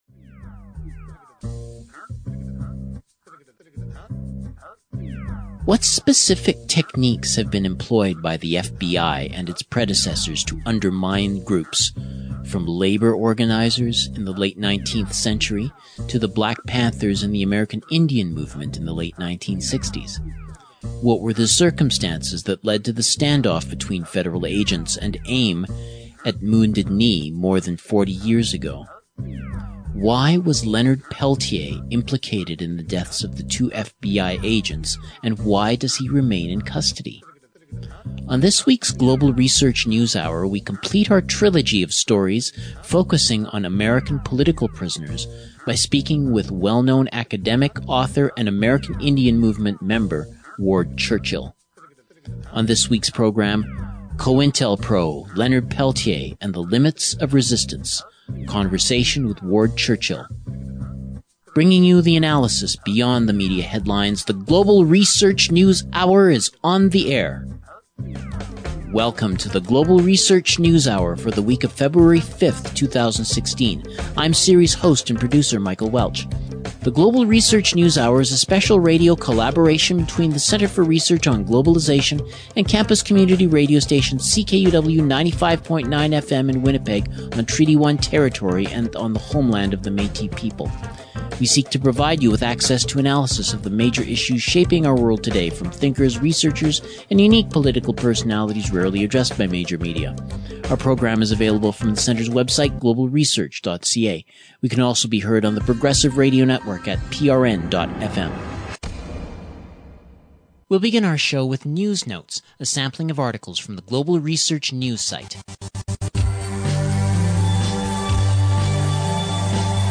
File Information Listen (h:mm:ss) 0:59:06 GRNH_feb5_2016_episode_130_session_mixdown.mp3 Download (5) GRNH_feb5_2016_episode_130_session_mixdown.mp3 42,559k 96kbps Stereo Listen All